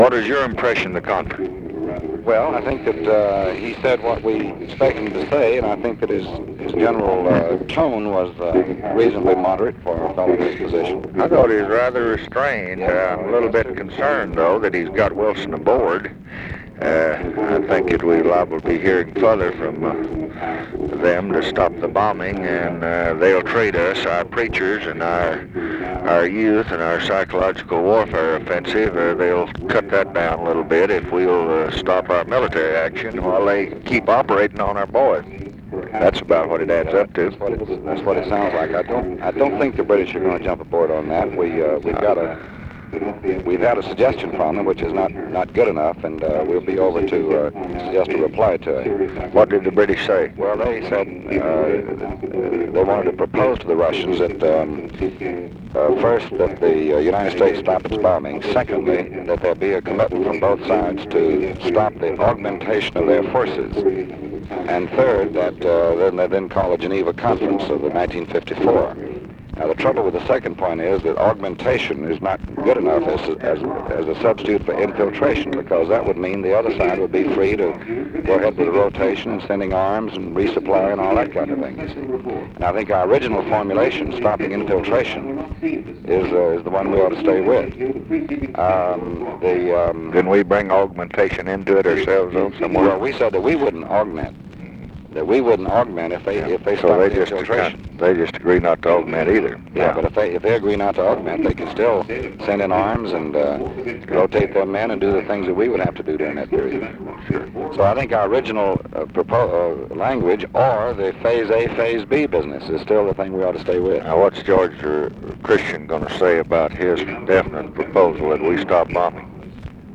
Conversation with DEAN RUSK, February 9, 1967
Secret White House Tapes